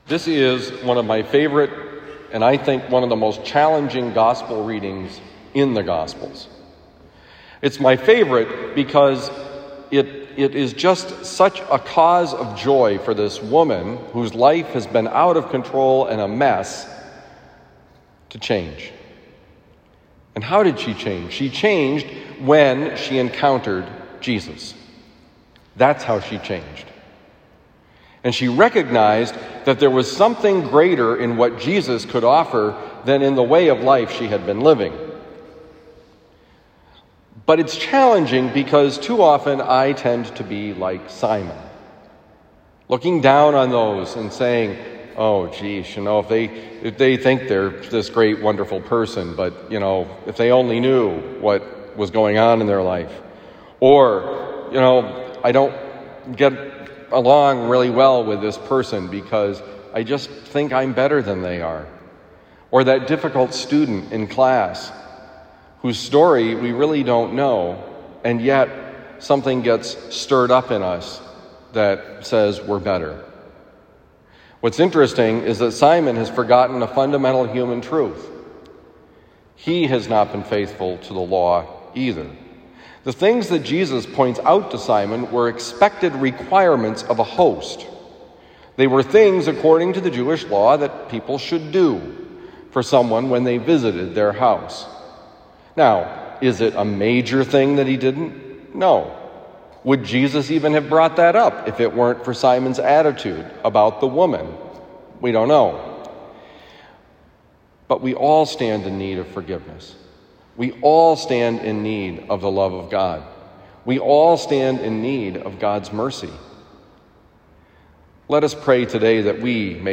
Homily for Thursday, September 16, 2021
Given at Christian Brothers College High School, Town and Country, Missouri.